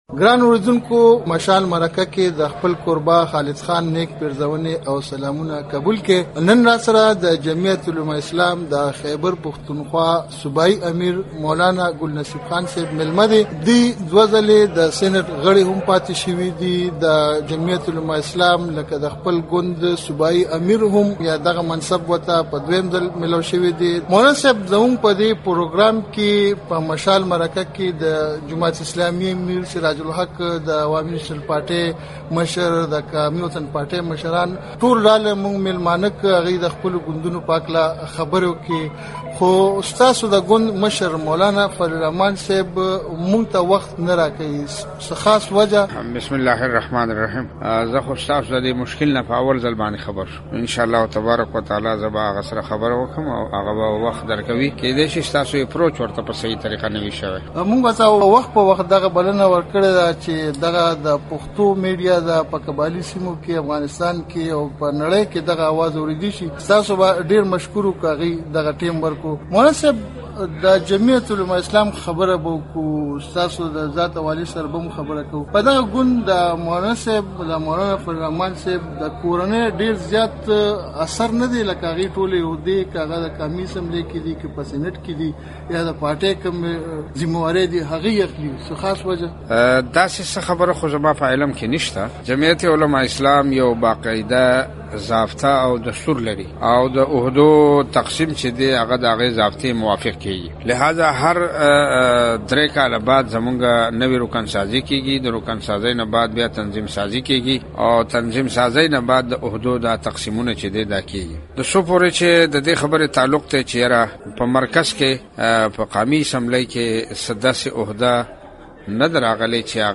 مولاناګل نصیب خان په مشال مرکه کې